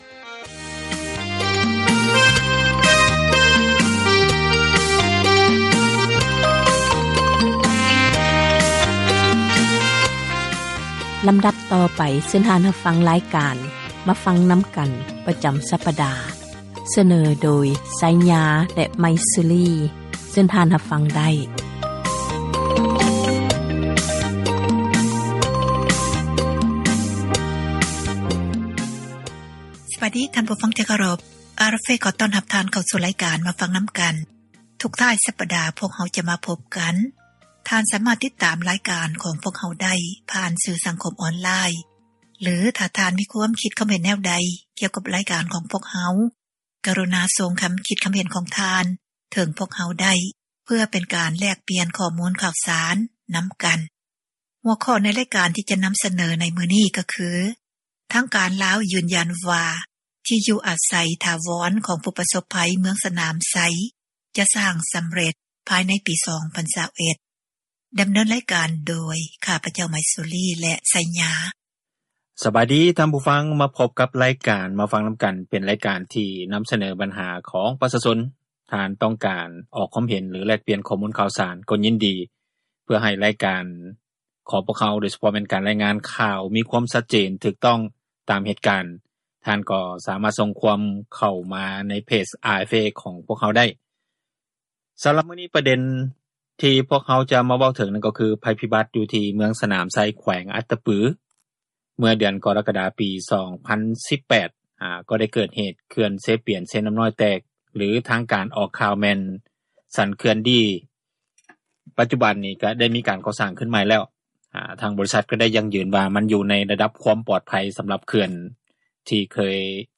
ການສົນທະນາ ໃນຫົວຂໍ້ ບັນຫາ ແລະ ຜົລກະທົບ ຢູ່ ປະເທດລາວ